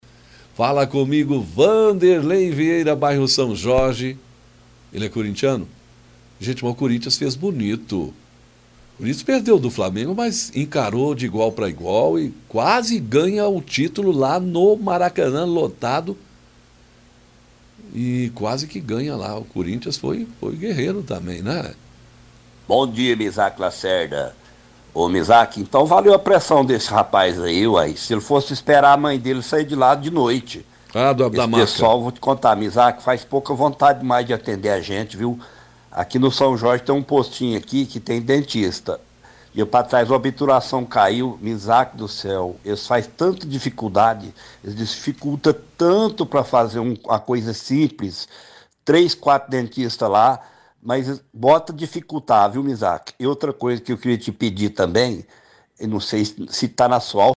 Ouvinte reclama que obturação caiu e teve muita dificuldade com atendimento de dentista no posto de saúde do São Jorge